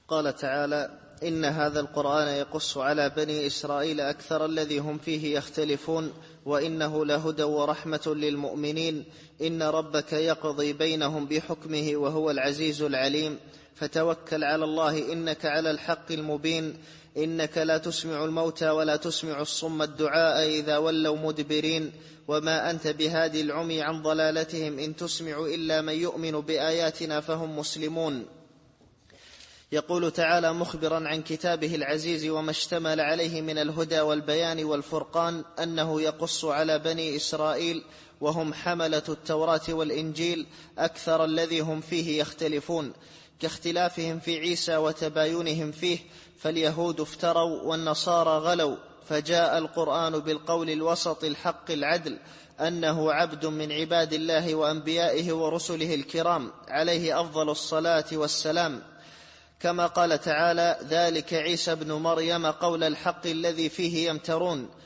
التفسير الصوتي [النمل / 76]